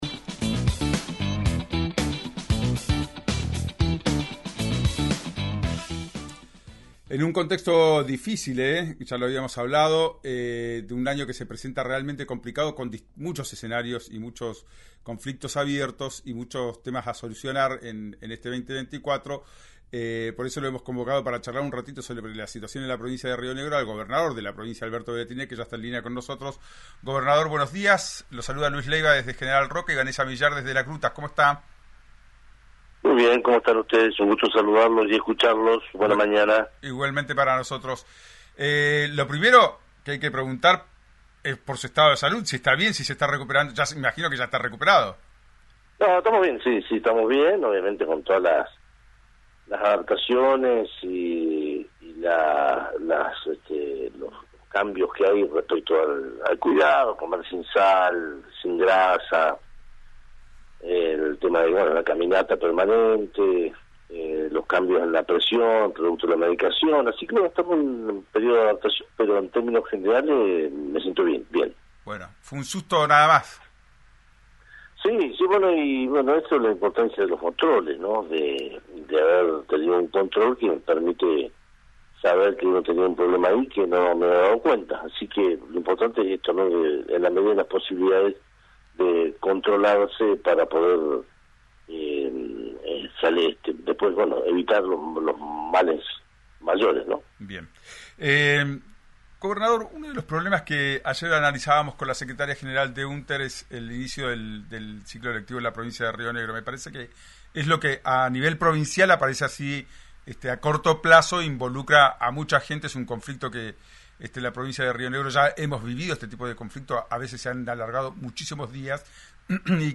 El gobernador Alberto Weretilneck en diálogo con RÍO NEGRO RADIO.